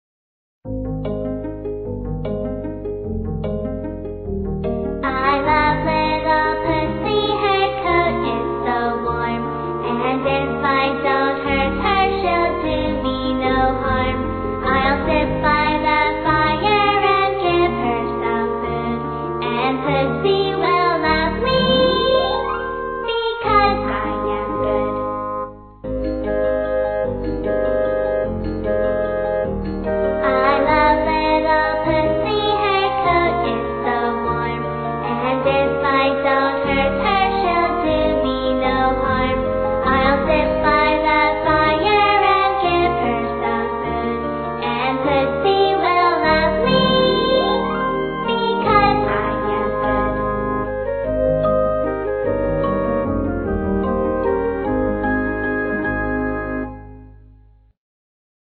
在线英语听力室英语儿歌274首 第85期:I Love Little Pussy的听力文件下载,收录了274首发音地道纯正，音乐节奏活泼动人的英文儿歌，从小培养对英语的爱好，为以后萌娃学习更多的英语知识，打下坚实的基础。